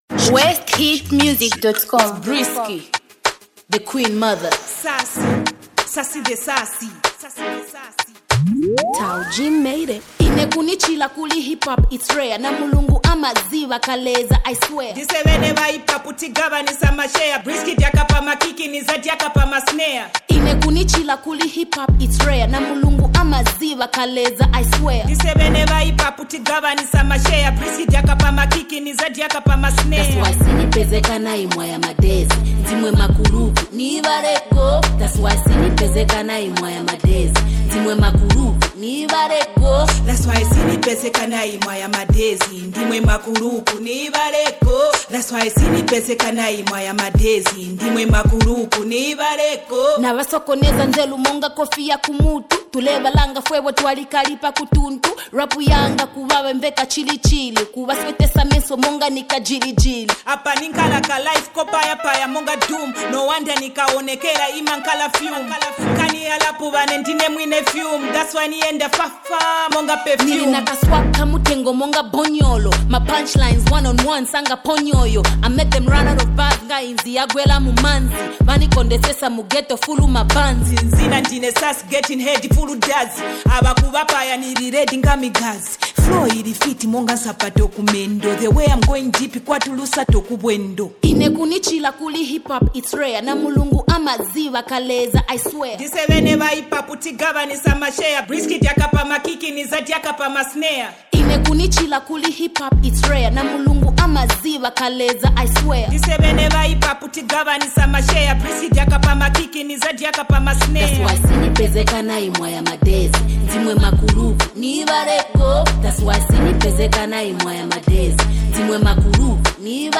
electrifying hip-hop track
featuring infectious beats and witty wordplay.